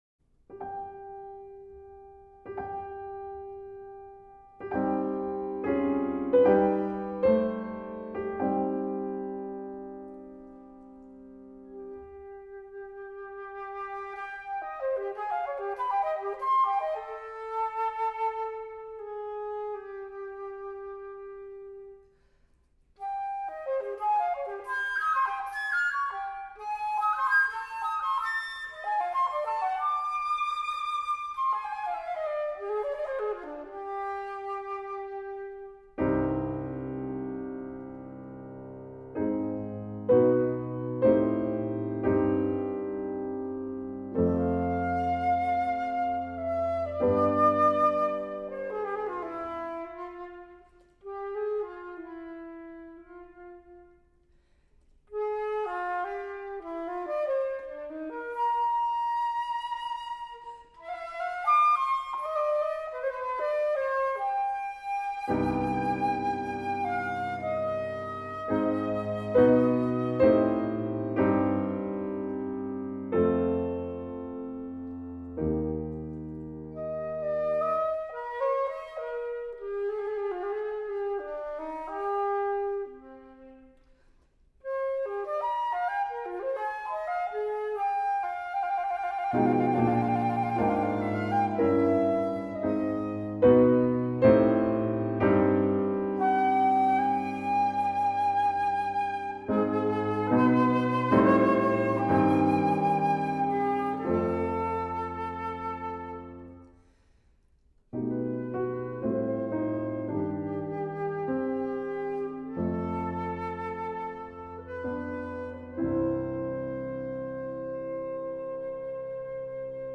For Flute and Piano.